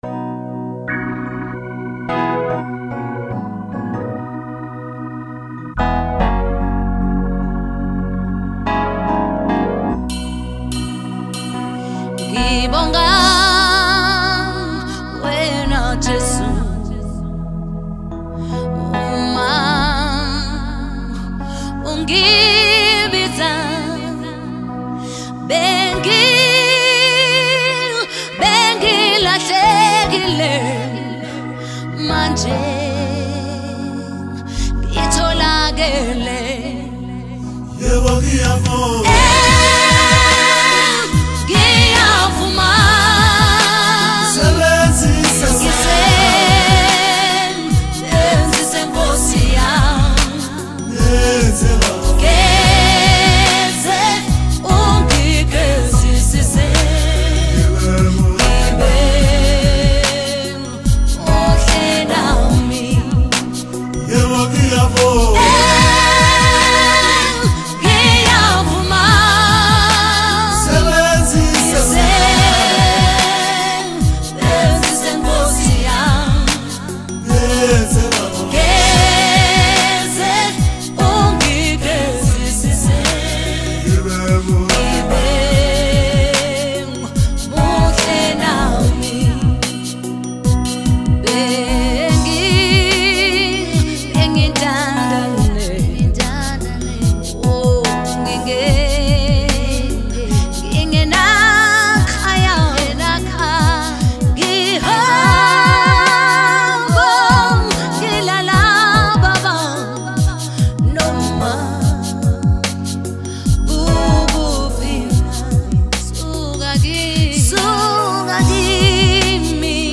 January 30, 2025 Publisher 01 Gospel 0